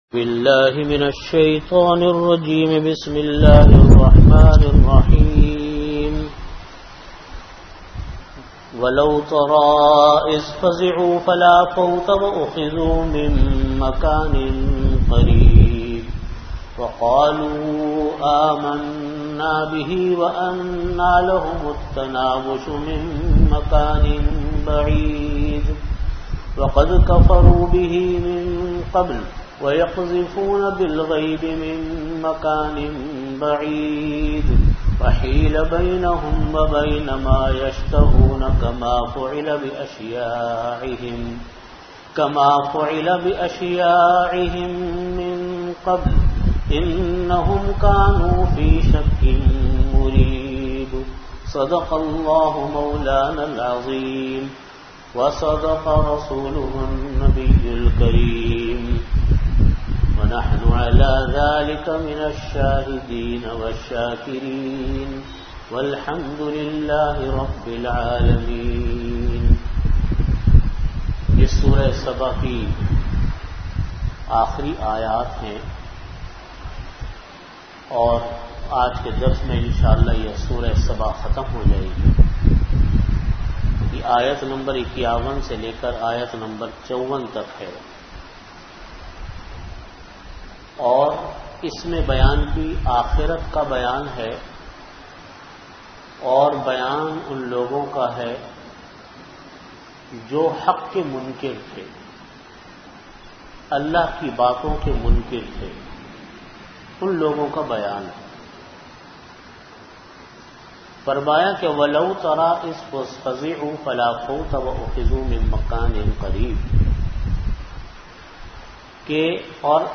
Category: Tafseer
37min Time: After Asar Prayer Venue: Jamia Masjid Bait-ul-Mukkaram, Karachi